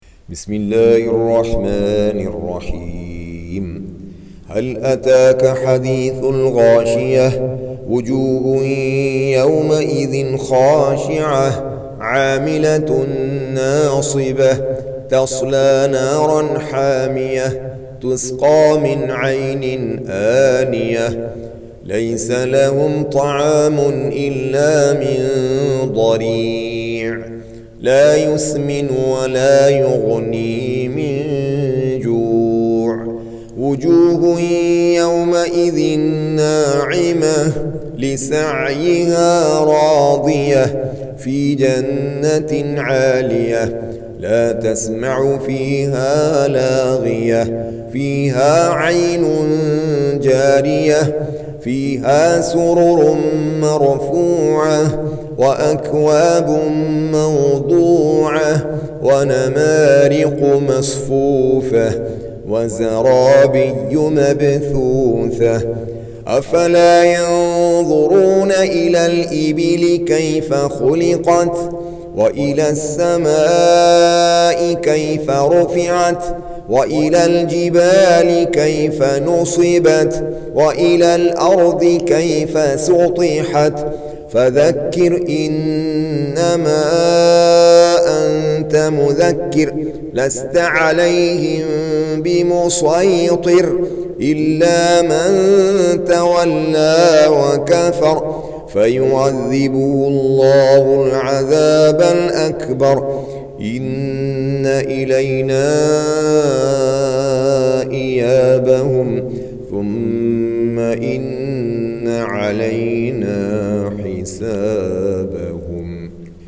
88. Surah Al-Gh�shiyah سورة الغاشية Audio Quran Tarteel Recitation
Surah Sequence تتابع السورة Download Surah حمّل السورة Reciting Murattalah Audio for 88. Surah Al-Gh�shiyah سورة الغاشية N.B *Surah Includes Al-Basmalah Reciters Sequents تتابع التلاوات Reciters Repeats تكرار التلاوات